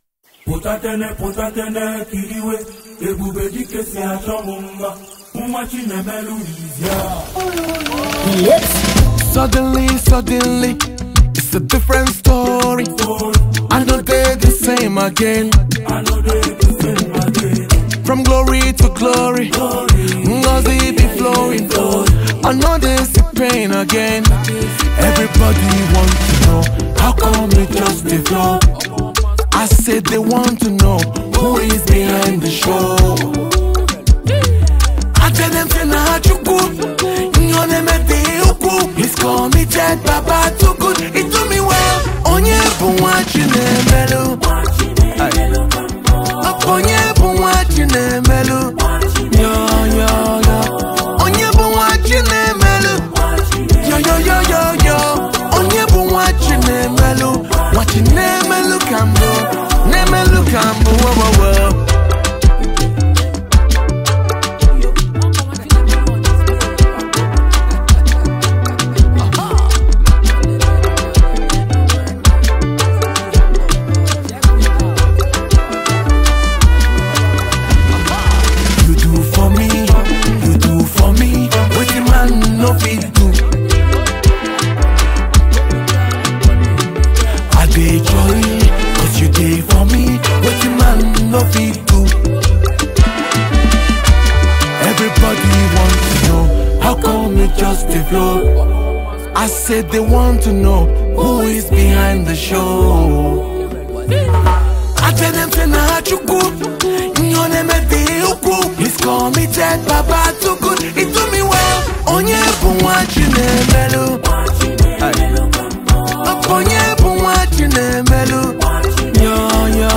Nigeria talented gospel music singer